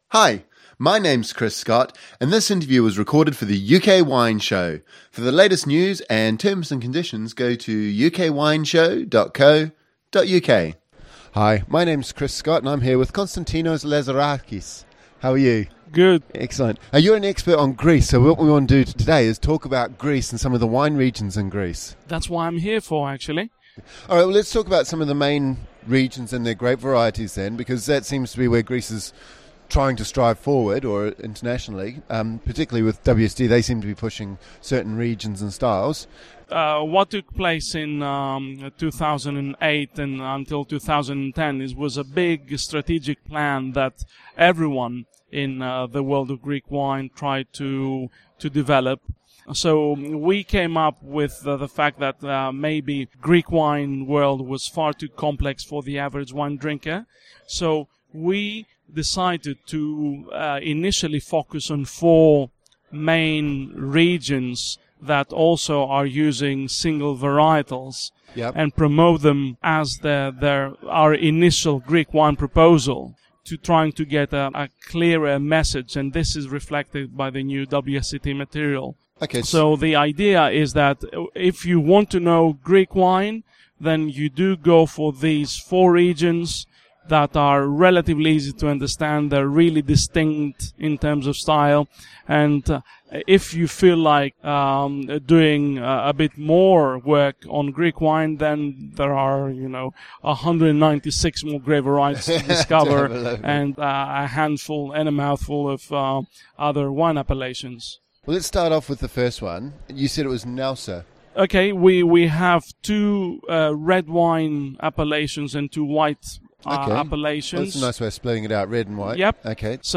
Tha main grape variety is Xinomavro [Ksee no' ma vro] grape (style close to Nebbiolo).
Assyrtiko [A seer' tee ko] very mineral not too aromatic.